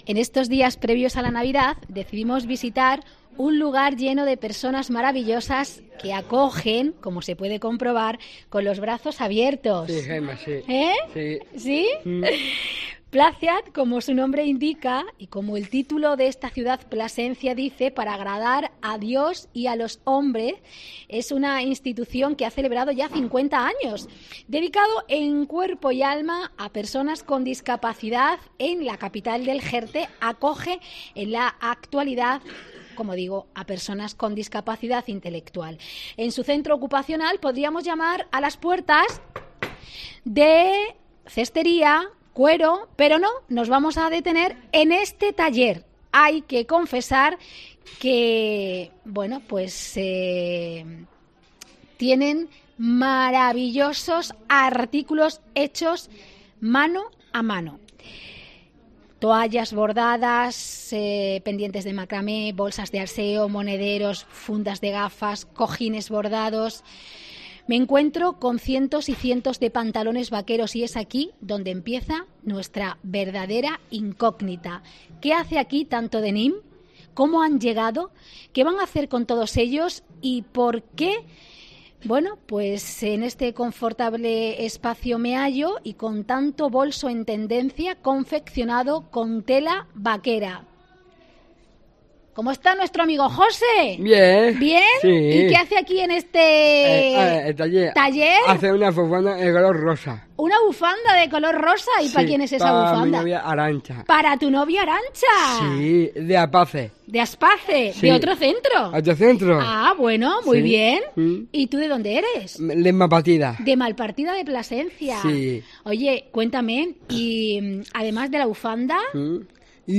Visita al taller de Costura de Placeat en Plasencia